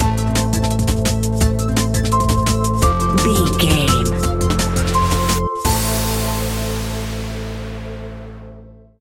Aeolian/Minor
Fast
aggressive
groovy
futuristic
frantic
drum machine
synthesiser
darkstep
sub bass
Neurofunk
synth leads
synth bass